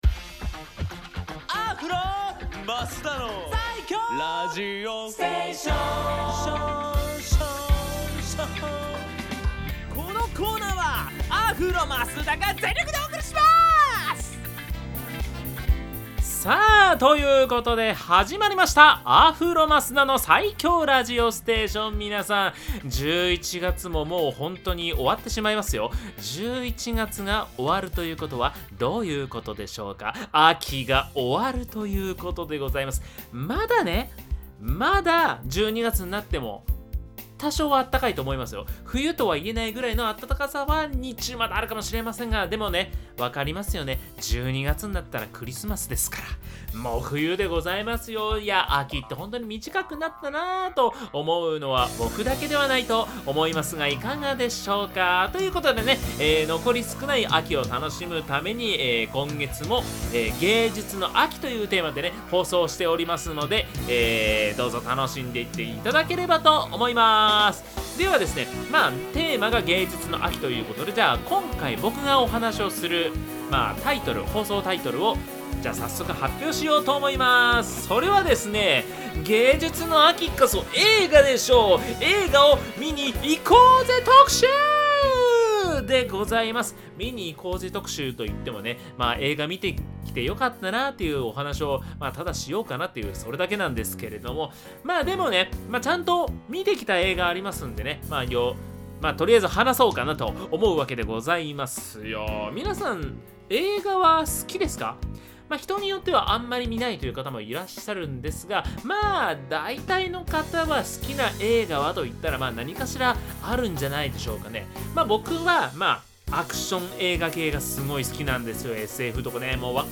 こちらのブログでは、FM83.1Mhzレディオ湘南にて放送されたラジオ番組「湘南MUSICTOWN Z」内の湘南ミュージックシーンを活性化させる新コーナー！